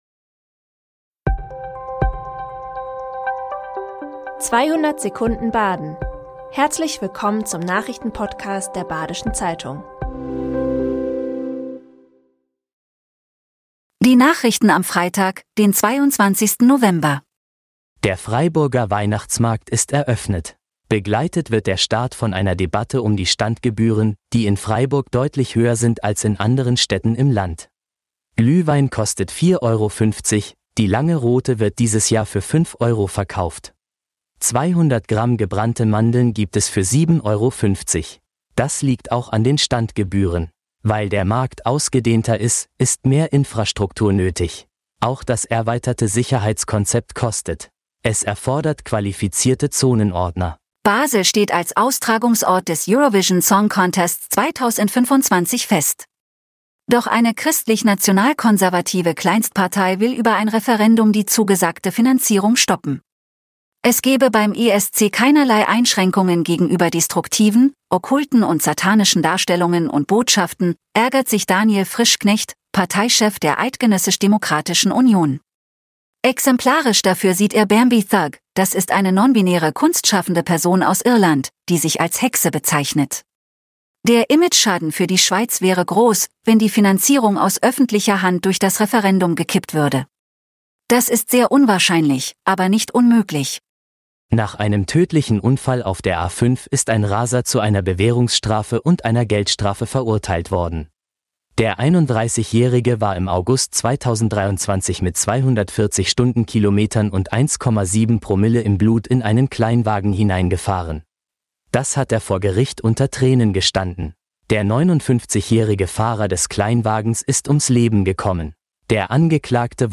“200 Sekunden Baden” ist der tägliche Nachrichten-Podcast der Badischen Zeitung. Montags bis freitags erscheint um 6.30 Uhr unser News-Format mit fünf wichtigen Nachrichten.
Die Nachrichten werden Ihnen in 200 Sekunden von einer künstlichen Computerstimme vorgelesen.